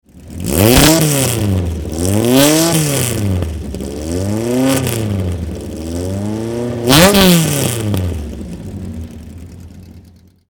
Ruapuna Raceway, Christchurch, New Zealand
Alfa Romeo GTAM Not sure if it was a real one, looked the part.
Car Sounds
sk03AlfaRomeoGTAM.mp3